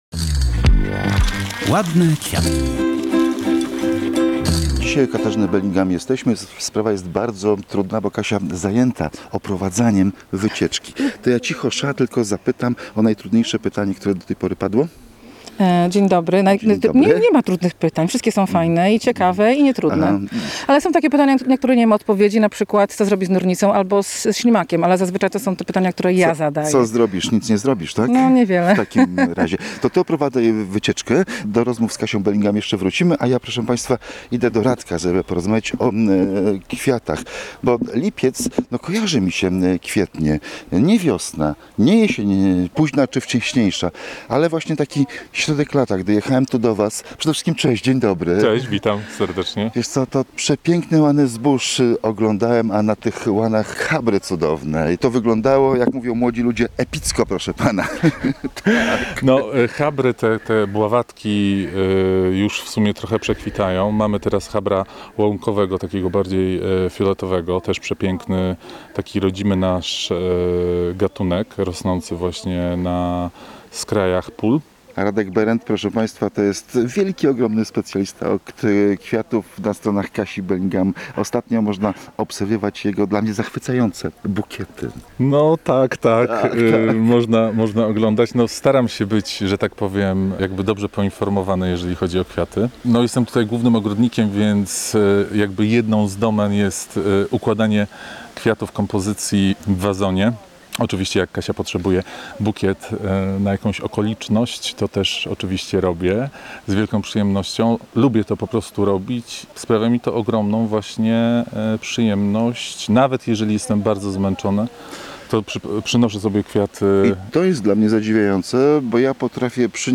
Ogrodniczka oprowadza po posesji, opowiada o roślinach.